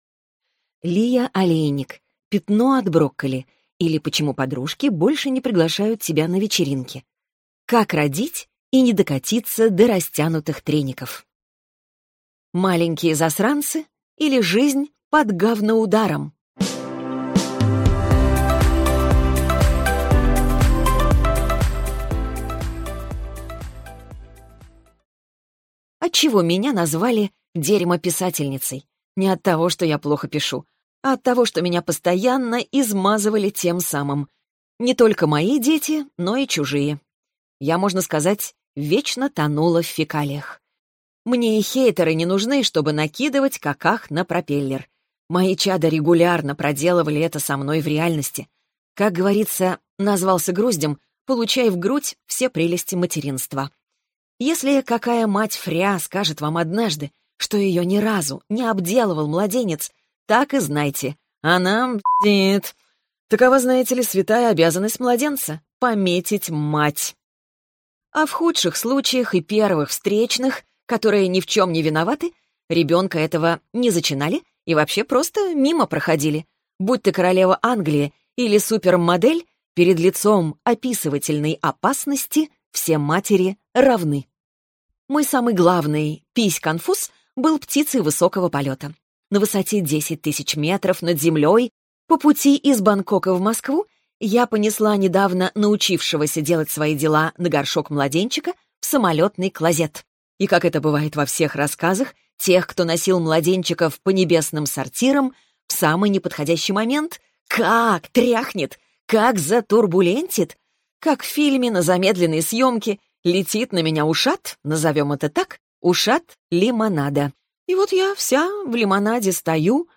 Аудиокнига Пятно от брокколи, или Почему подружки больше не приглашают тебя на вечеринки. Как родить и не докатиться до растянутых треников | Библиотека аудиокниг